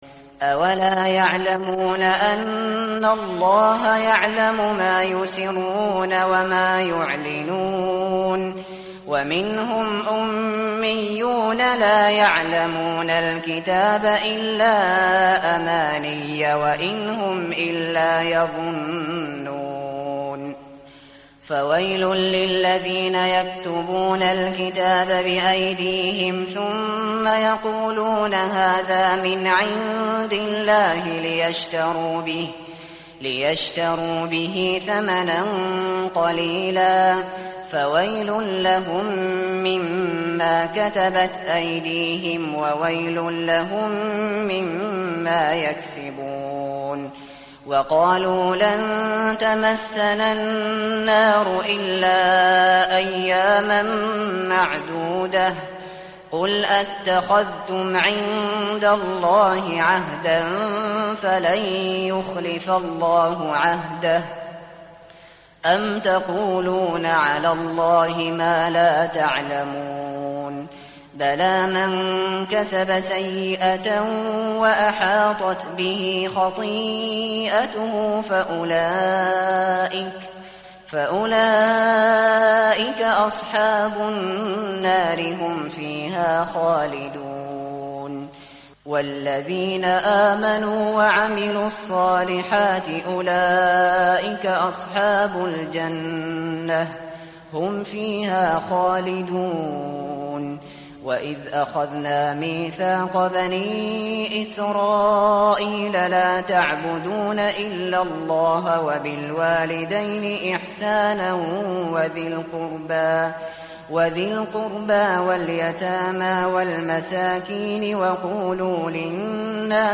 متن قرآن همراه باتلاوت قرآن و ترجمه
tartil_shateri_page_012.mp3